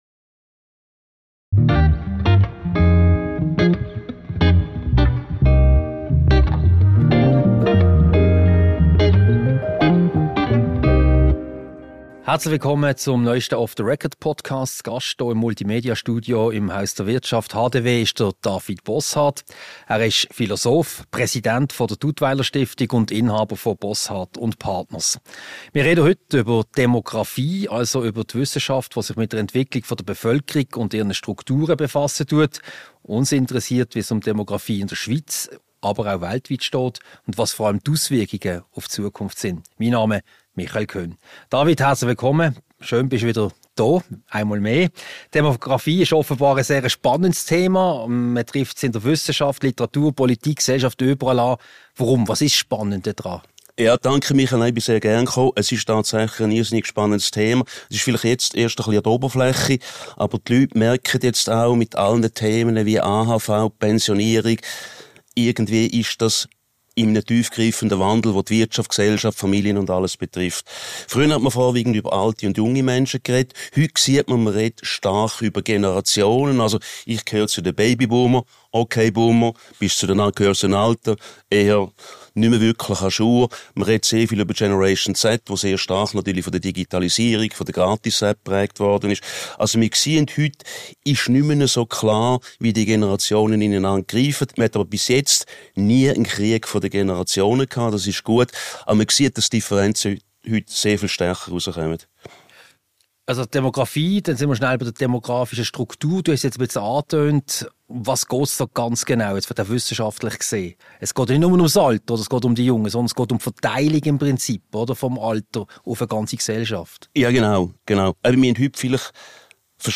Ein Gespräch über Demographie, die Unterschiede zwischen den Generationen und was das für die Gesellschaft und Wirtschaft bedeutet.